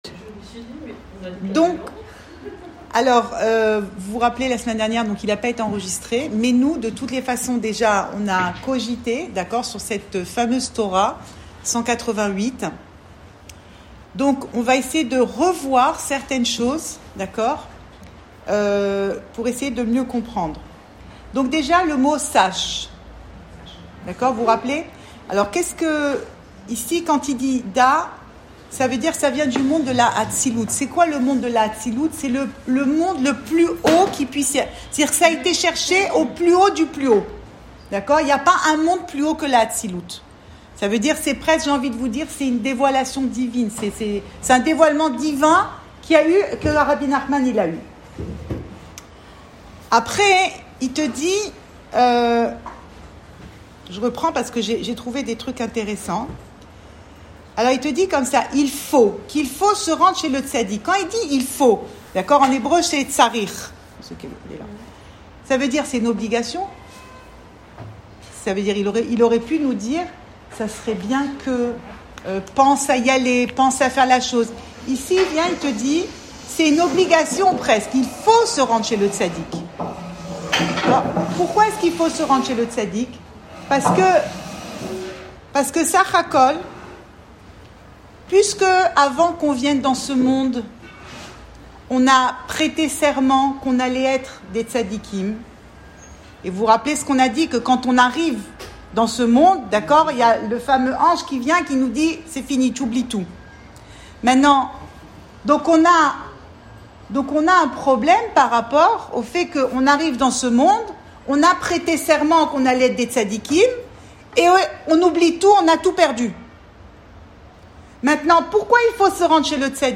Cours audio Le coin des femmes Pensée Breslev - 2 décembre 2020 6 décembre 2020 Retrouver ce que l’on a perdu. Enregistré à Tel Aviv